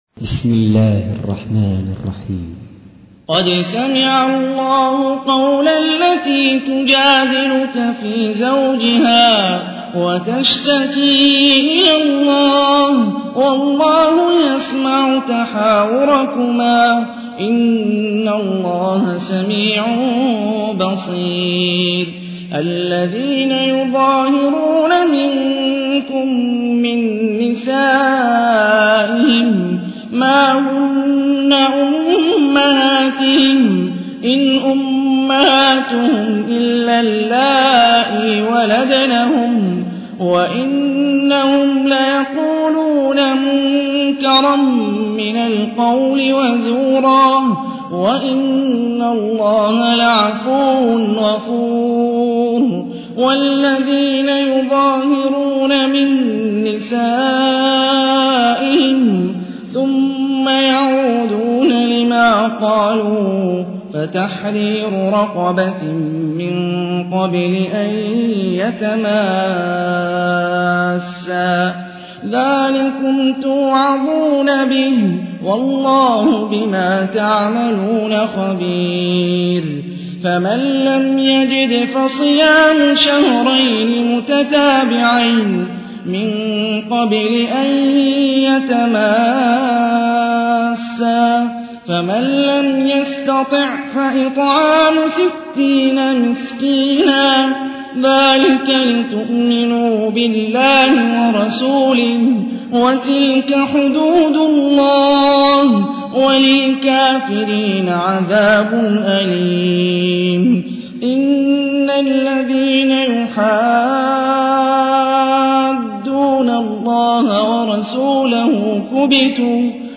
تحميل : 58. سورة المجادلة / القارئ هاني الرفاعي / القرآن الكريم / موقع يا حسين